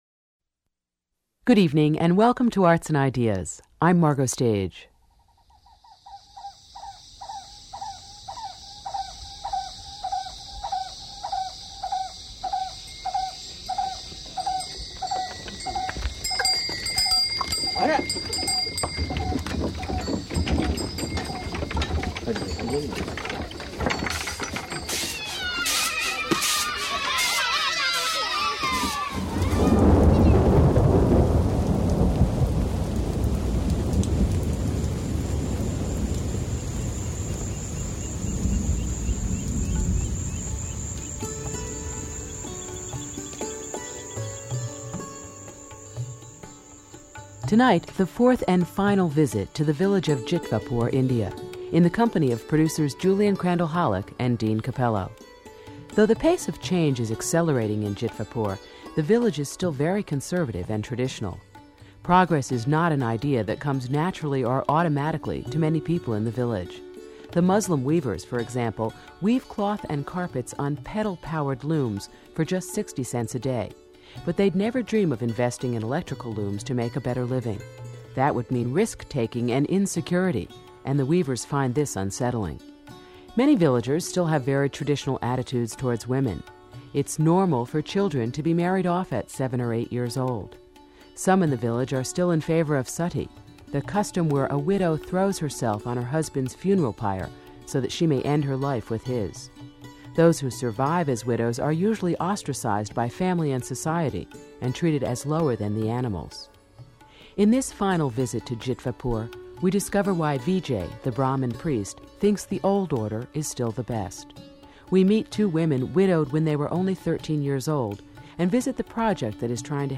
Saturday midday, near the Community Hall 6.